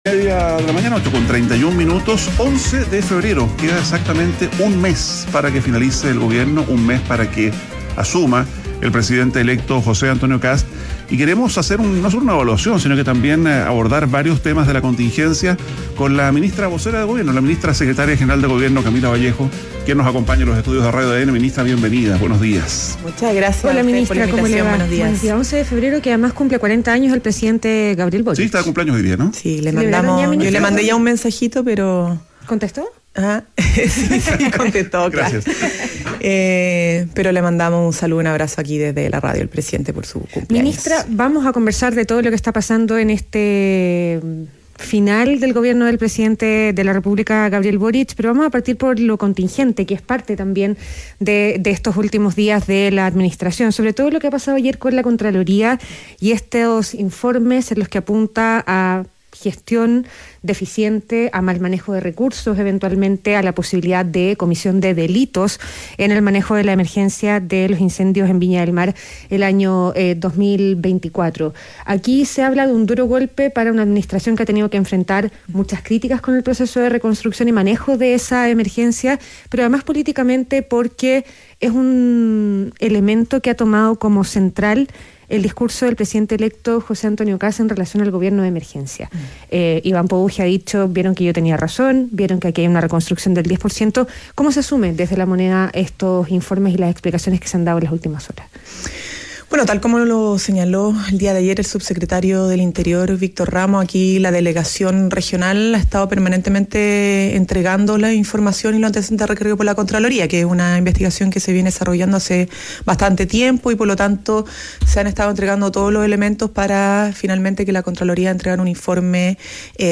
En conversación con ADN Hoy, la ministra se refirió a este tema y también al proceso de reconstrucción.